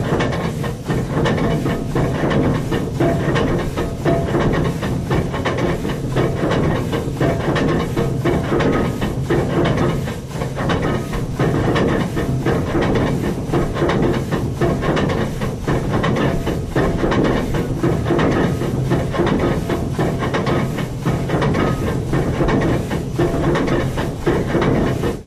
Anchor is dropped and retrieved. Boat, Anchor Engine, Steamship